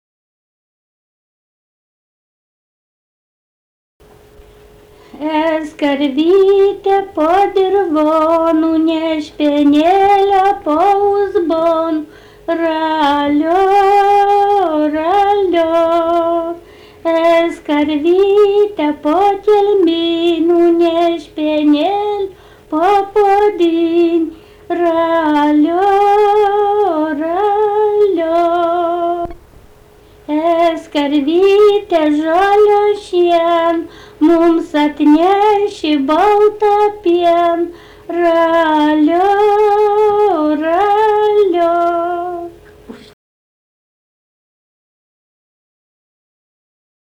daina, vaikų
Šimonys
vokalinis